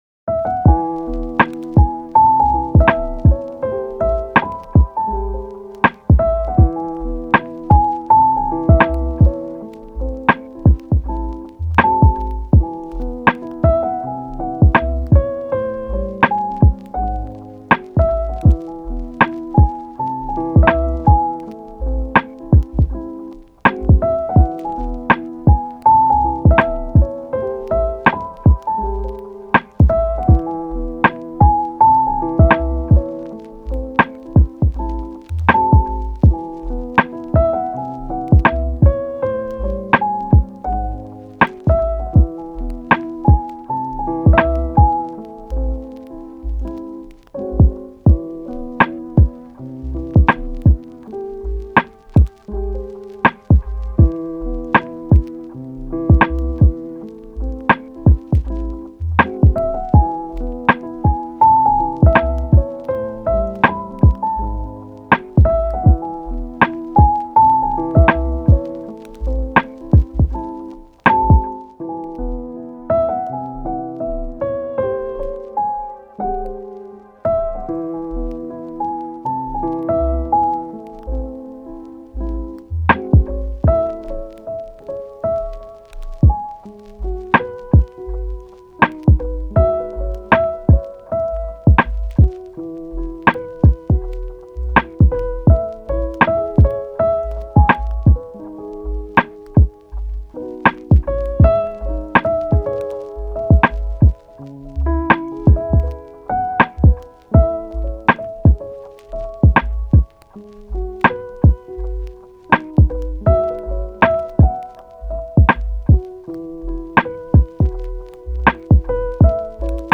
チル・穏やか
メロウ・切ない